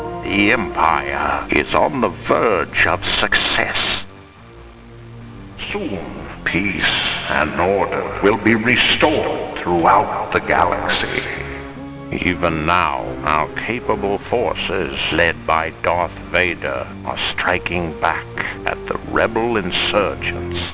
Emperor Gives Rousing Speech on Coruscant
Speech.wav